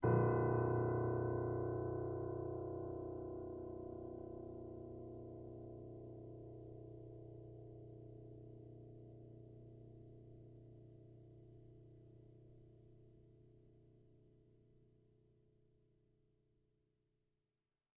标签： pedal horror sustain detuned piano string old